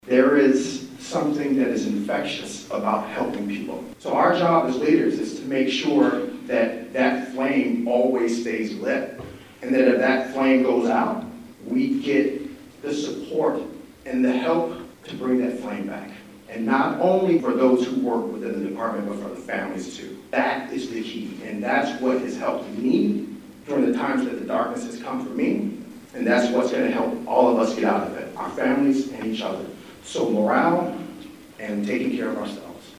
The Riley County Law Board held a three hour public forum at the Manhattan Fire Department Headquarters.
Montpelier, Vermont Police Chief Brian Peete spoke about legitimacy as a central theme, noting the police department can’t take care of others if it isn’t taking care of itself.